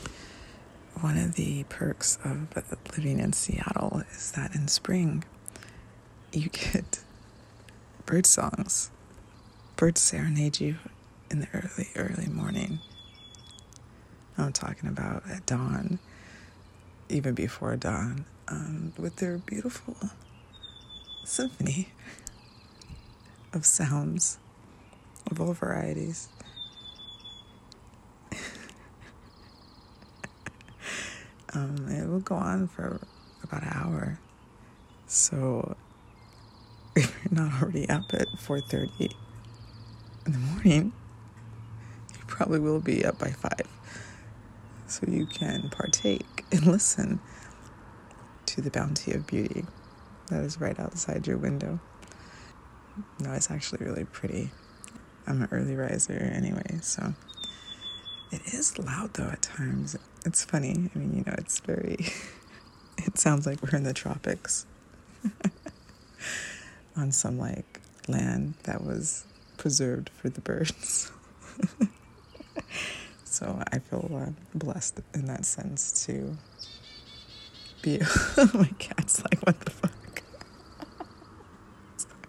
early in the morning…birdsongs
birdsongs.mp3